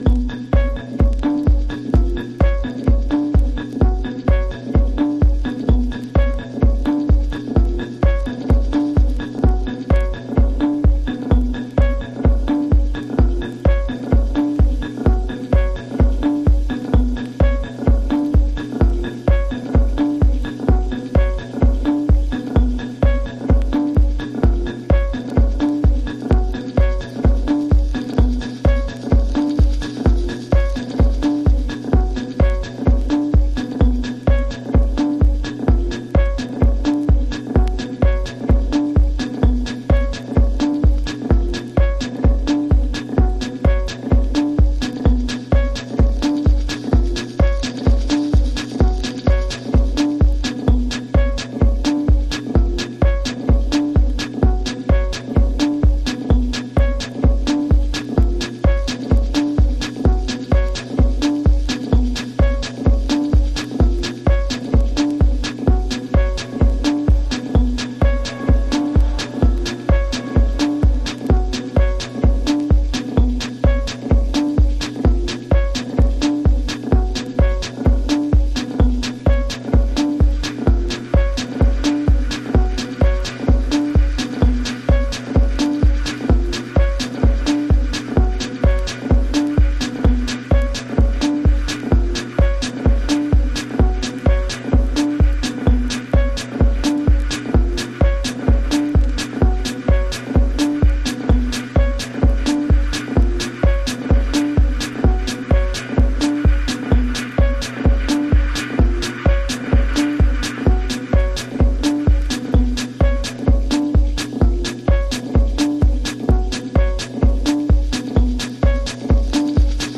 House / Techno
硬質なビートで疾走、ひたすら地を這うようなテクノトラックス。